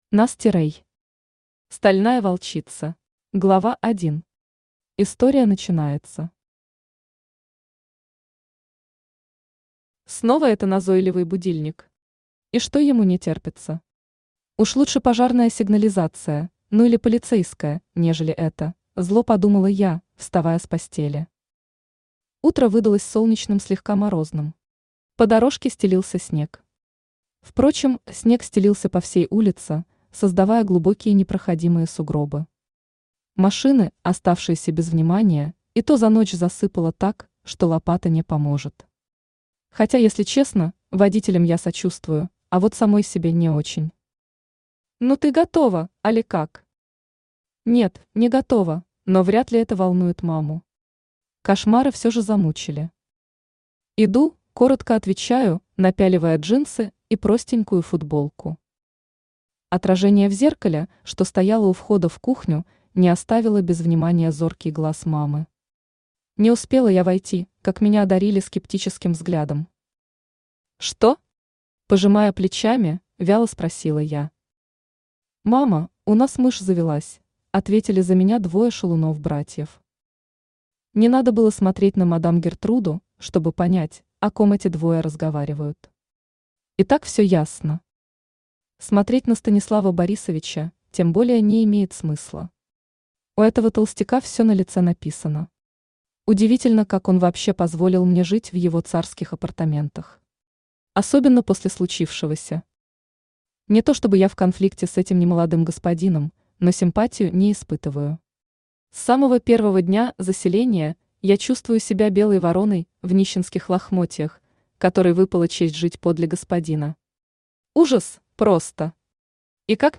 Аудиокнига Стальная волчица | Библиотека аудиокниг
Aудиокнига Стальная волчица Автор Nasty Rey Читает аудиокнигу Авточтец ЛитРес.